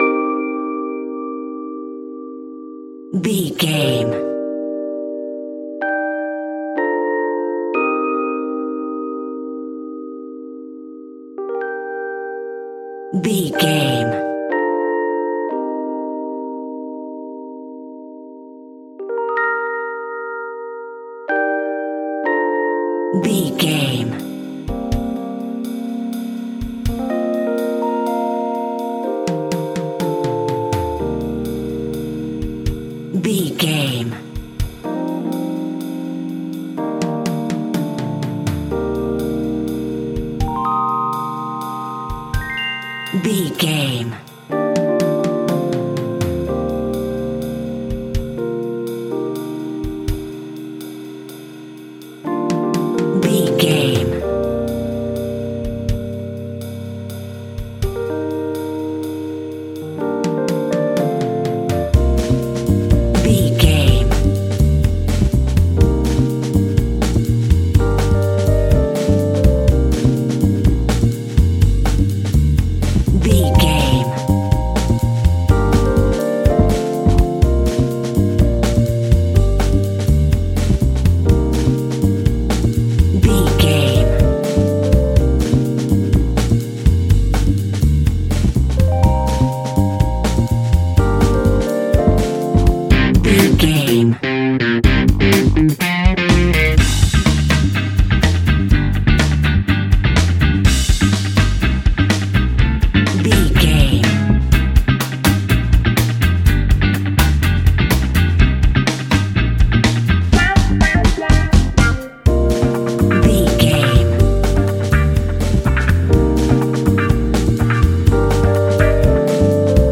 Aeolian/Minor
energetic
romantic
percussion
electric guitar
acoustic guitar